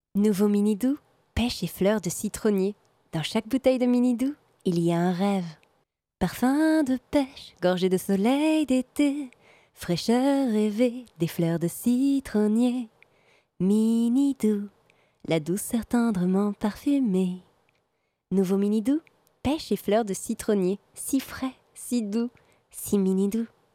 Comédienne voix off pub et fiction, je suis chroniqueuse à la radio, je me tiens à votre disposition !
Sprechprobe: Werbung (Muttersprache):
voice over, radio, actress